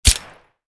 wpn_pistol_10mm_silenced_3d.wav